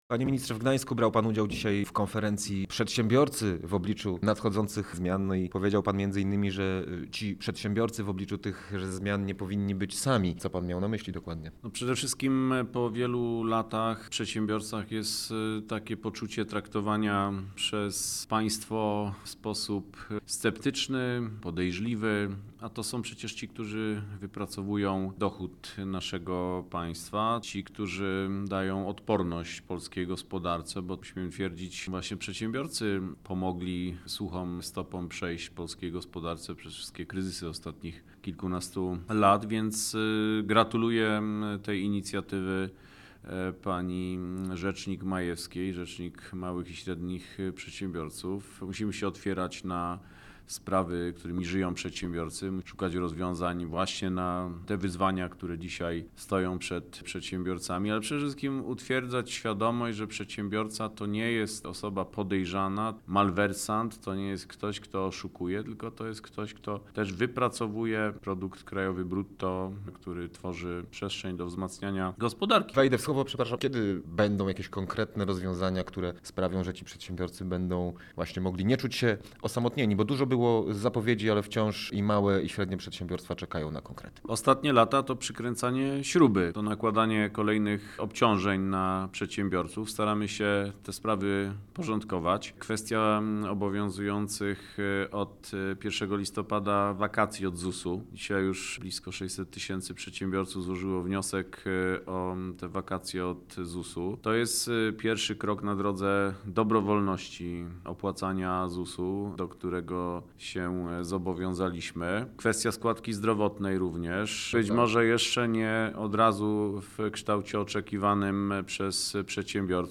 Posłuchaj rozmowy z Ministrem Rozwoju i Technologii Krzysztofem Paszykiem.